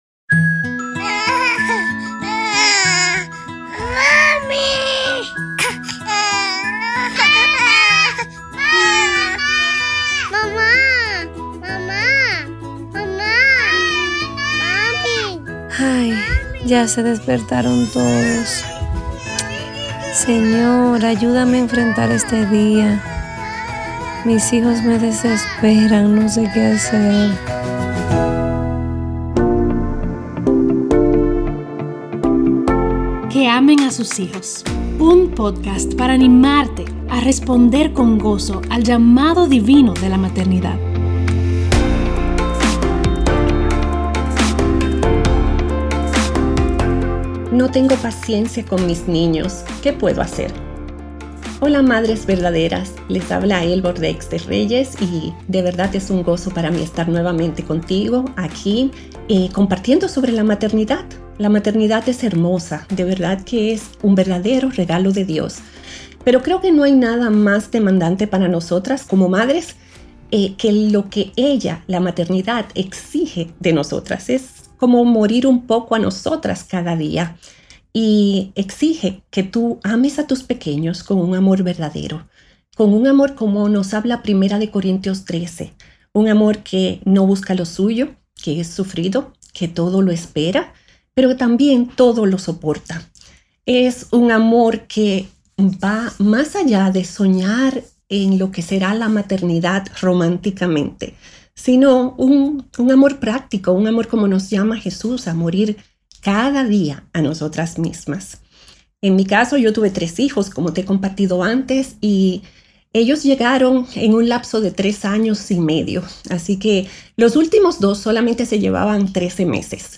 Cuando hay hijos pequeños en el hogar, las responsabilidades pueden parecer más importantes que instruir el corazón de tus hijos, mas esto no debe ser así. ¡Recibe ánimo y consejo de una madre anciana en el episodio de hoy!